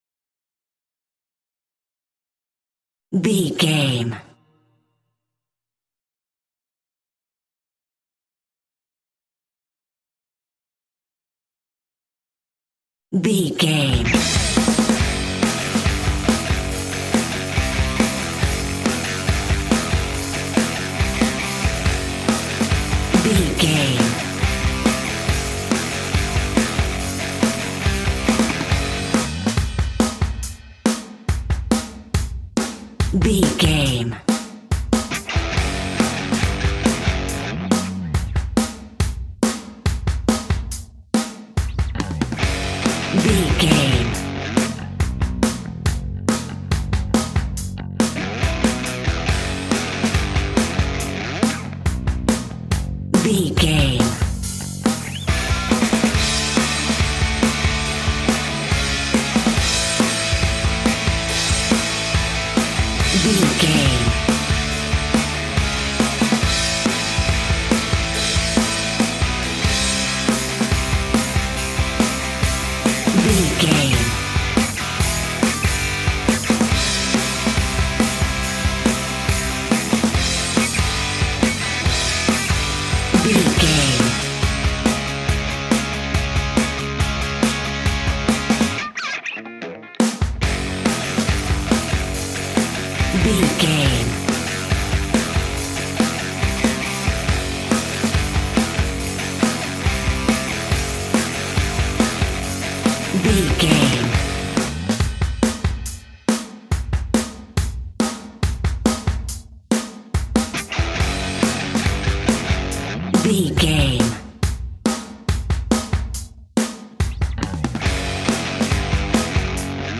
Angry Grunge Rock Alt.
Aeolian/Minor
F#
hard rock
heavy rock
guitars
dirty rock
rock instrumentals
Heavy Metal Guitars
Metal Drums
Heavy Bass Guitars